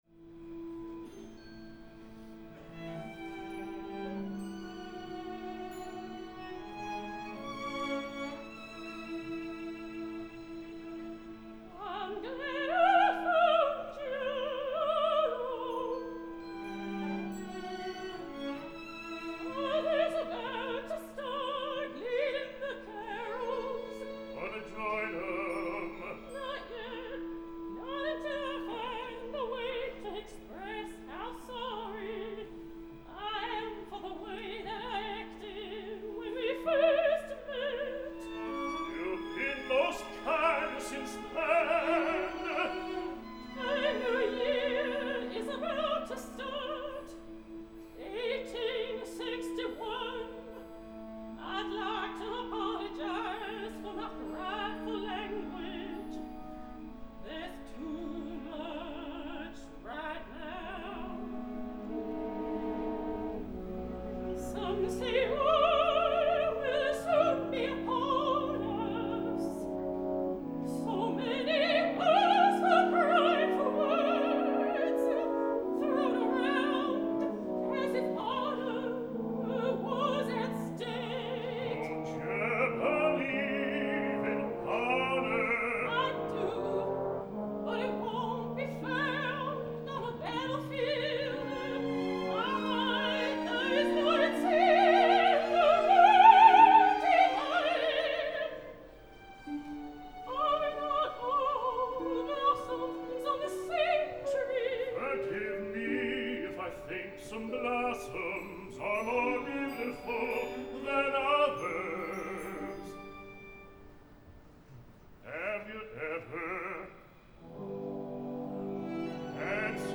DUETS:
Instrumentation: Soprano & Baritone